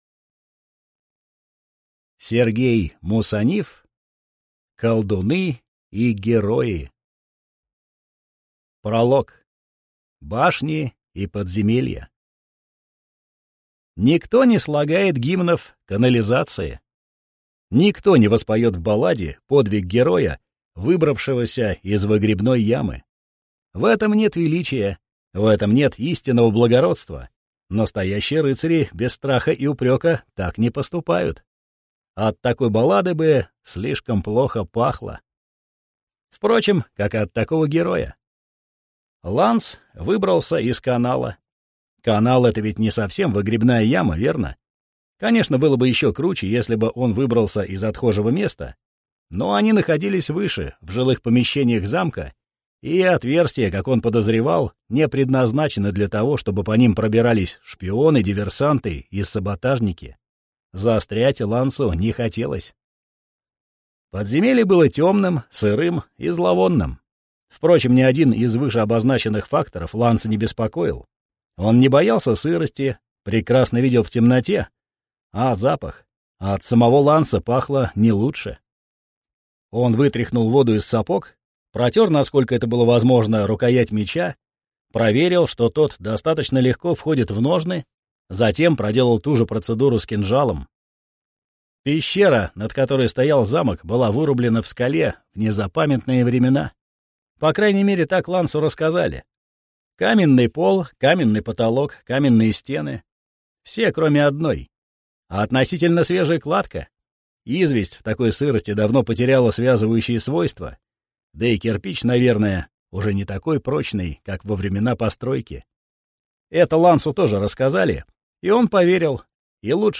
Аудиокнига «Колдуны и герои».